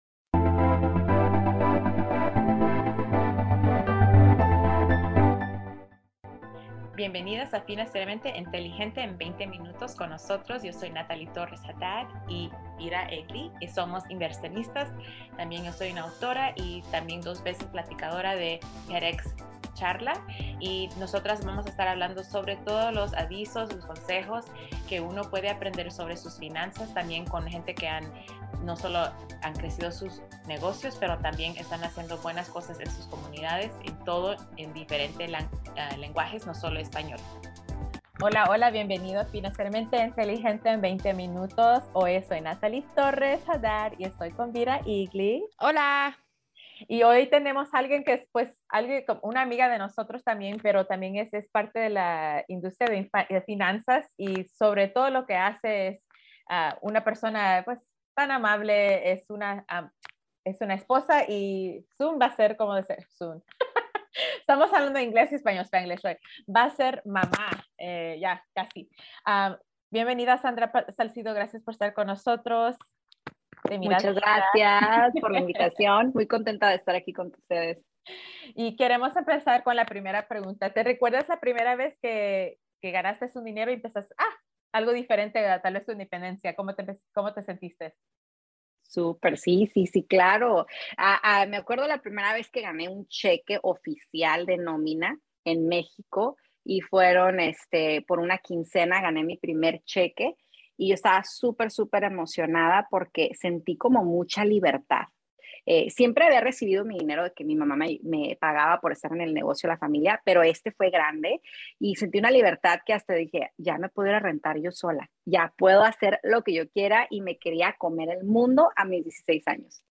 Prepárate para una charla real sobre bebés y presupuestos.